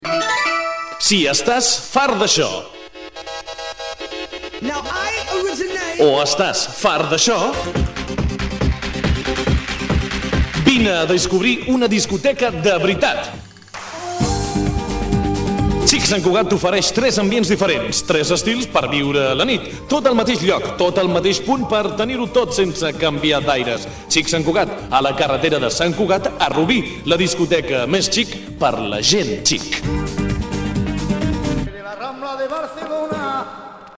Voz grave versátil, dulce y natural, y a veces fuerte.
kastilisch
Sprechprobe: Werbung (Muttersprache):
Versatile voice, sweet and warm, and sometimes strong